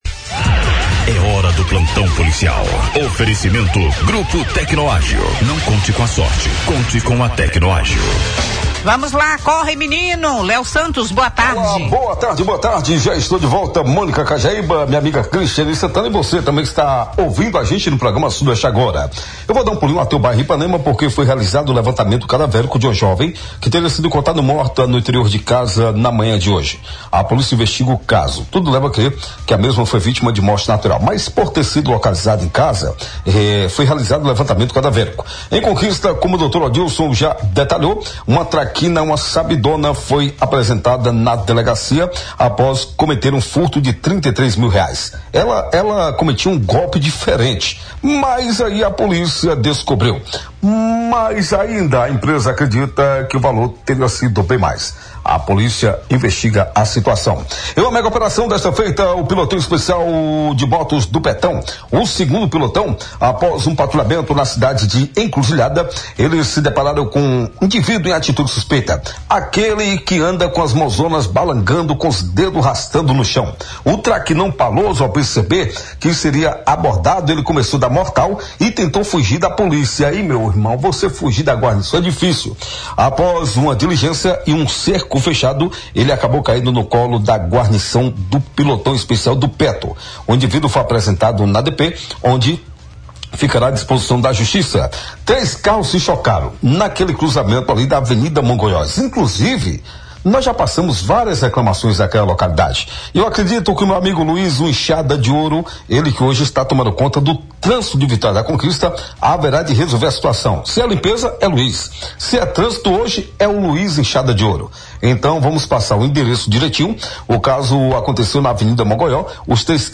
O Plantão Policial do Sudoeste Agora, programa da Rádio Clube de Conquista, apresentou um resumo dos principais fatos ocorridos nas últimas 24 horas na Joia do Sertão Baiano. Entre os destaques desta quarta-feira (27) estão a prisão de uma mulher investigada por desviar valores de uma loja e a morte de uma jovem na Zona Sul de Vitória da Conquista.